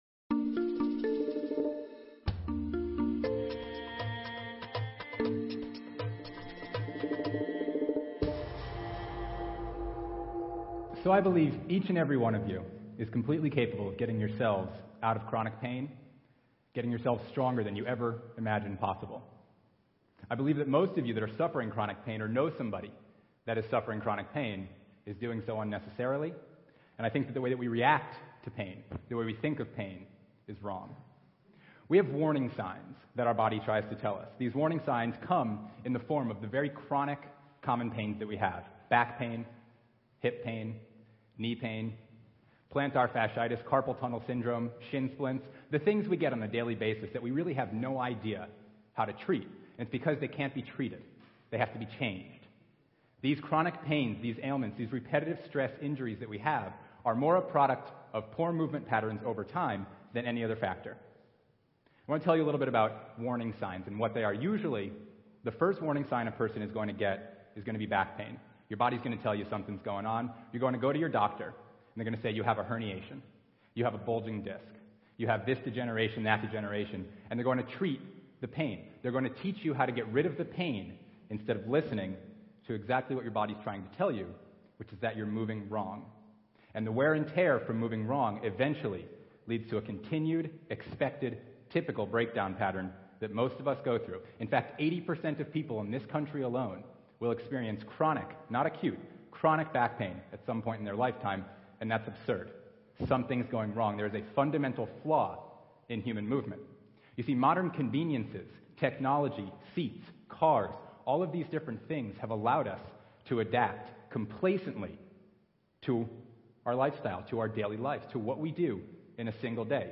TEDx Talk
TEDxAmericanRiviera